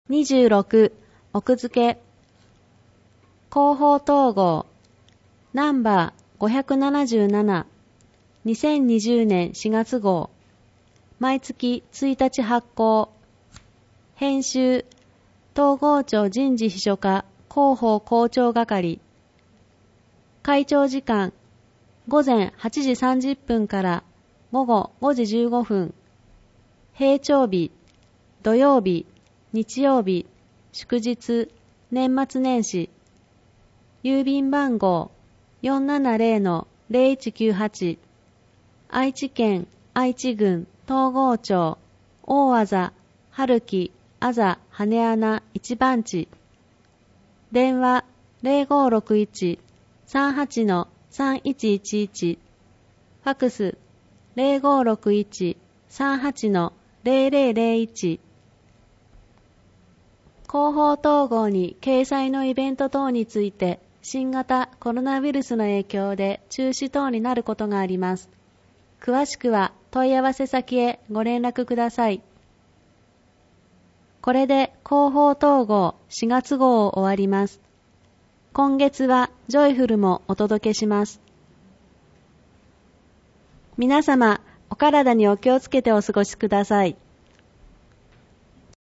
広報とうごう音訳版（2020年4月号）